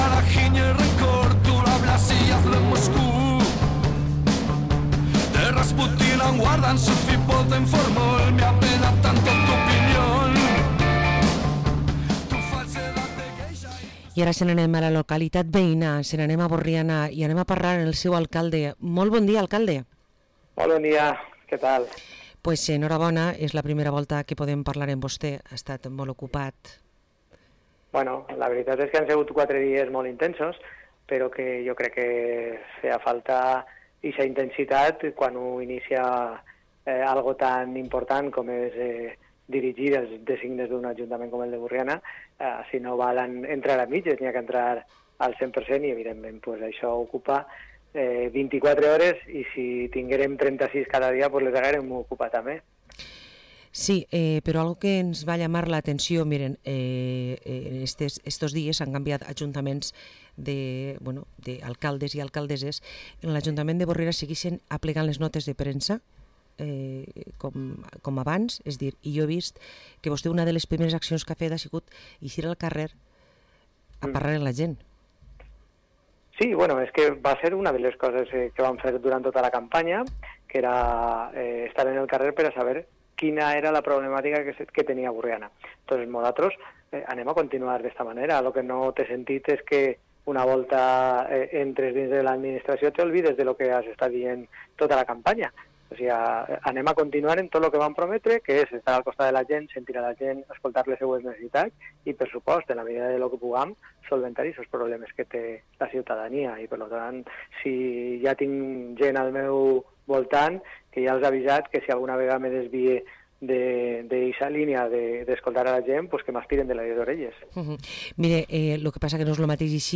Entrevista a Jorge Monferrer, alcalde de Borriana